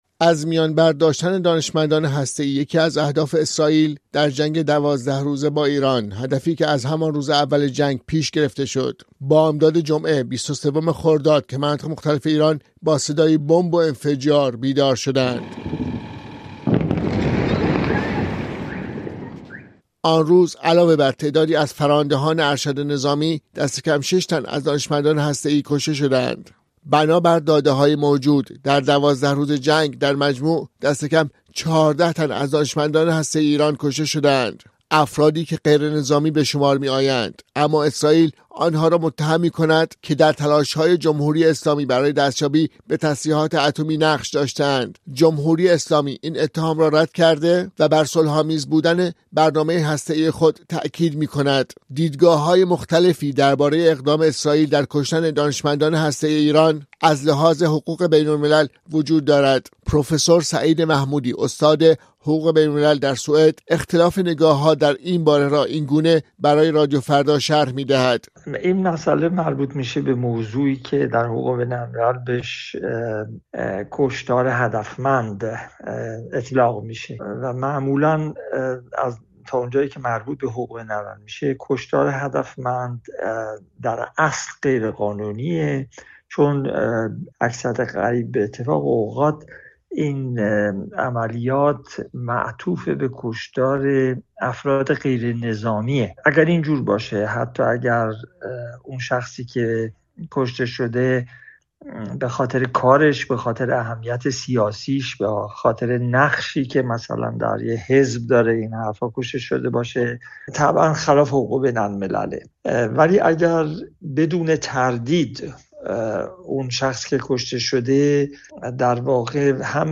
در این باره با رادیوفردا گفت‌وگو کرده است